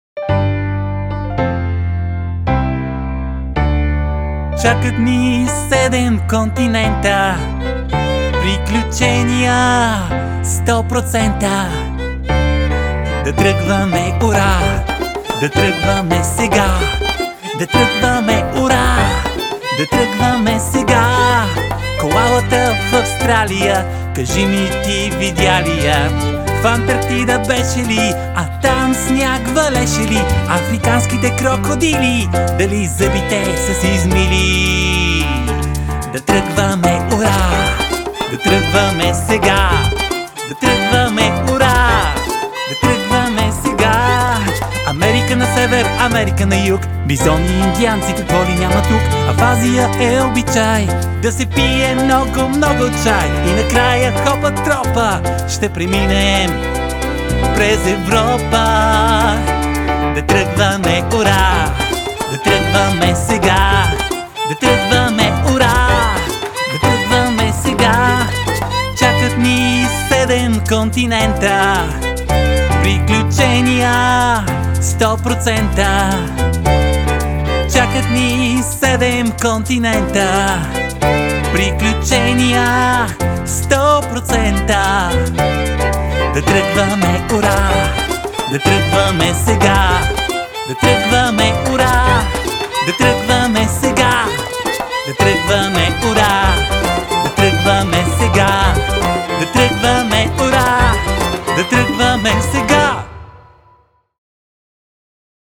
20 авторски детски песнички